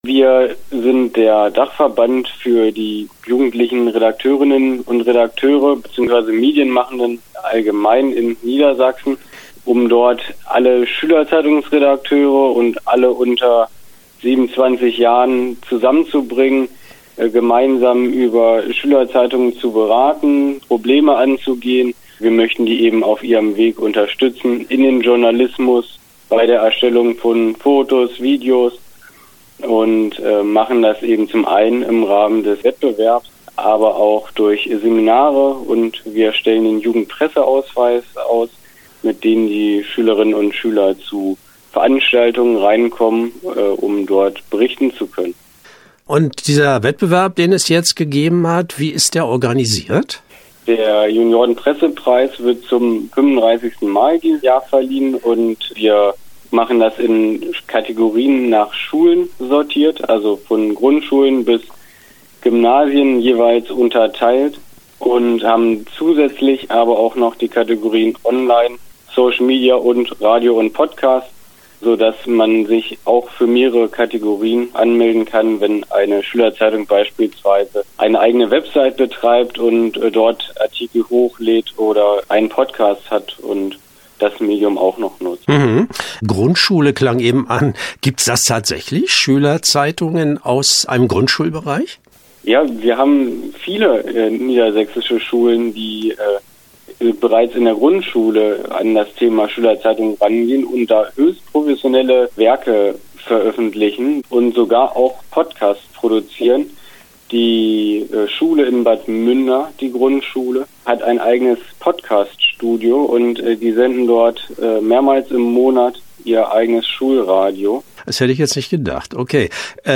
Interview-Junioren-Pressepreis_db.mp3